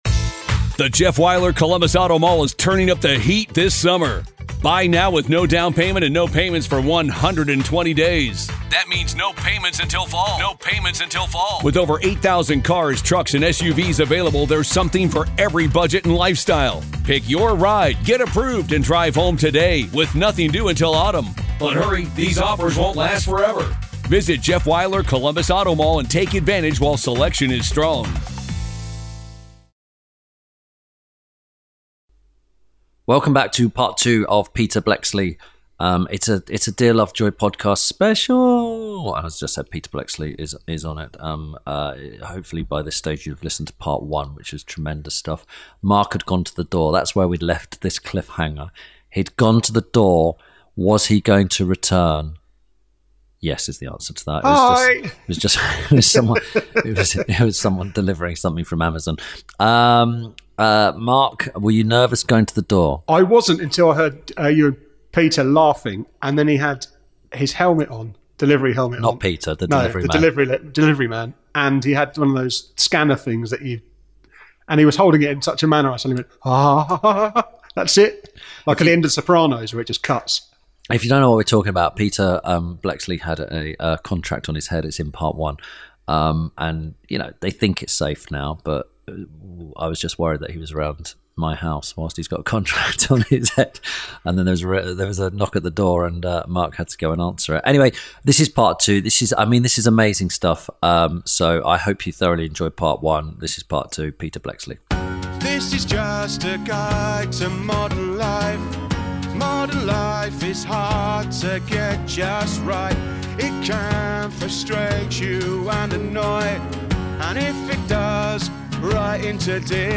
Part two of Tim Lovejoy talking to founder member of Scotland Yard’s undercover unit, author, and now TV presenter, Peter Bleksley. Tim and Peter discuss string vests, reforming drug laws and sticking to what you’re good at.